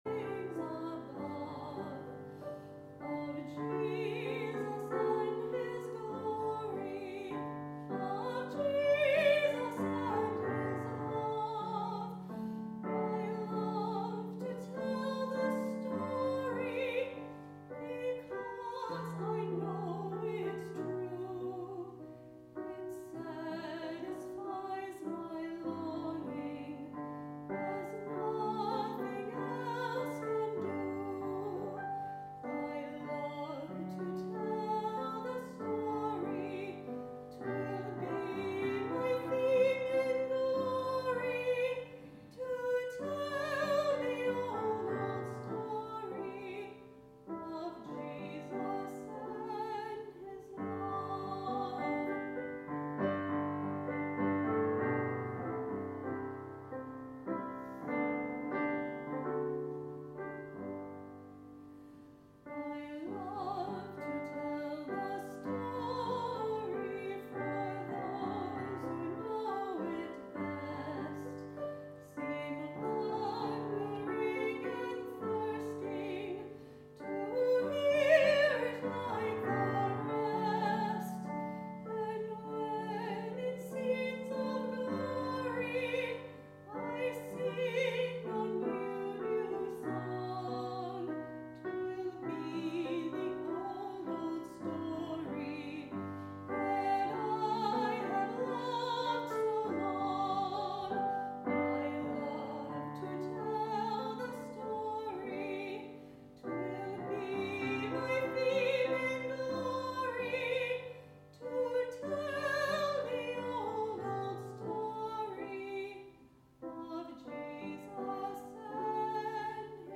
Sermon – You Need to Love Me, Too